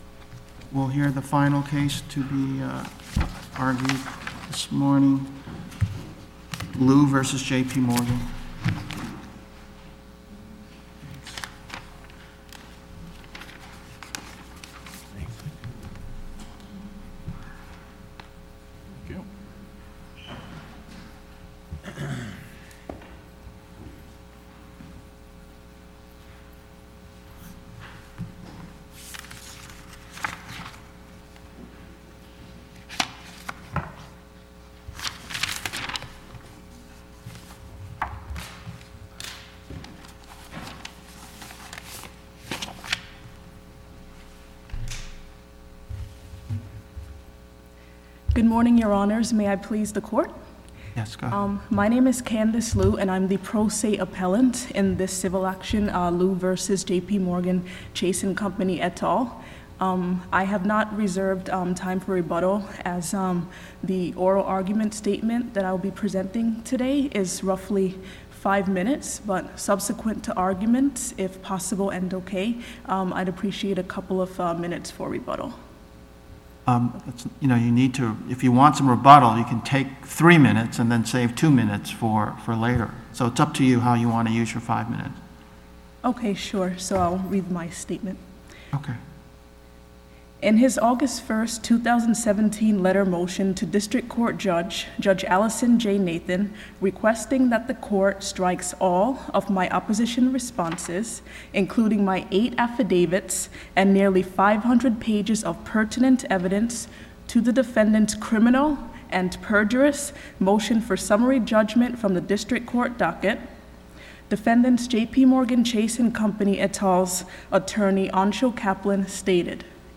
THE AUDIO OF MY “FIVE MINUTE” APPELLANT ORAL ARGUMENT OF APRIL 18, 2019